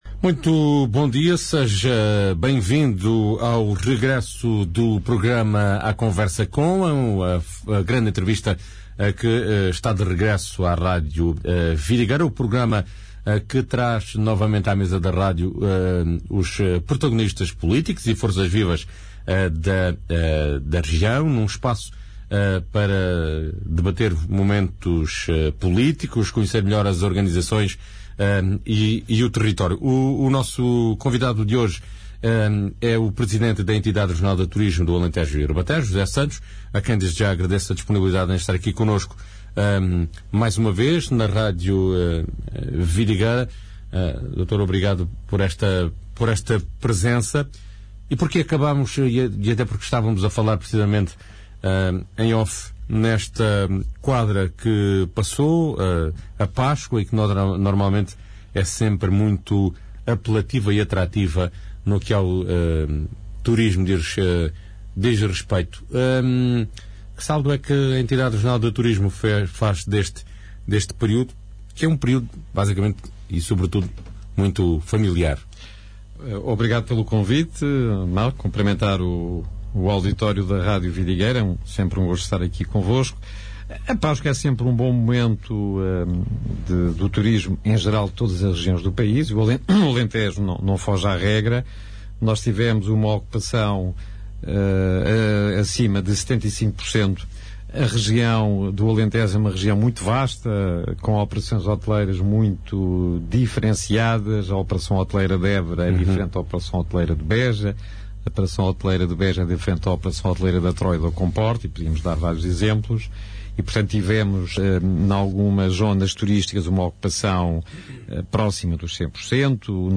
entrevista
José Santos, Presidente da Entidade Regional de Turismo do Alentejo e Ribatejo é o convidado desta sexta-feira 10 de Abril.